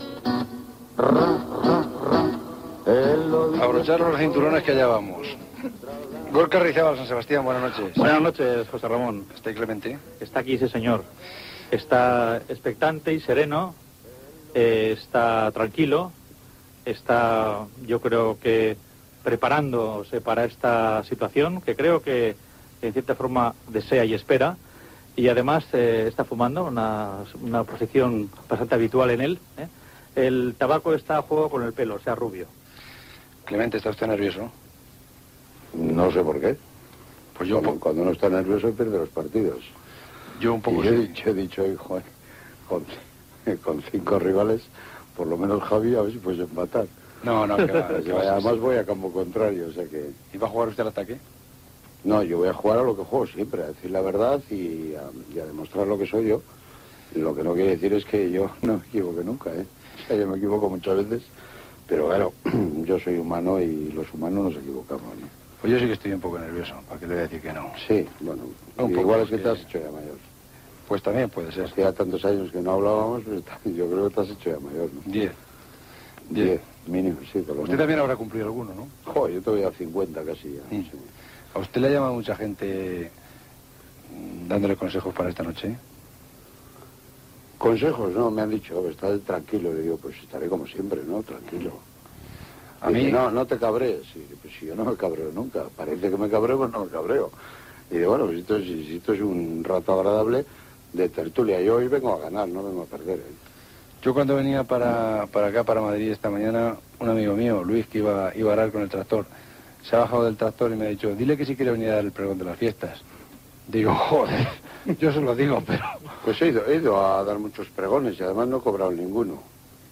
Sintonia, presentació i entrevista a l'entrenador de futbol Javier Clemente